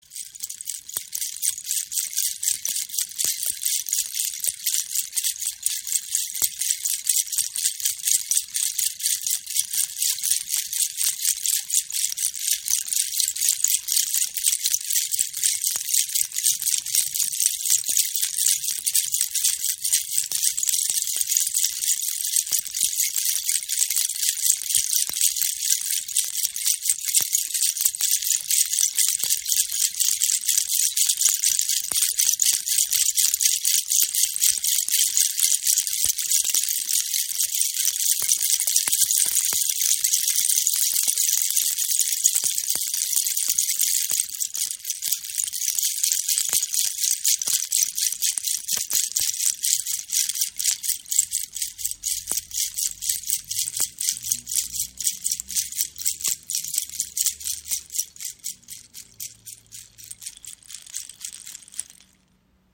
Rassel der Shipibo | Kreis-Shaker | Kokos im Raven-Spirit WebShop • Raven Spirit
Klangbeispiel
Wunderschöne in Handarbeit hergestellte Shipibo Rassel aus halbirten Baby Kokosnüssen an einem mit gekordelten Fäden umspannten Ring. Erzeugt einen sanft rieselnden Rasselsound. Der Sound dieser Rasseln ist klar und hell.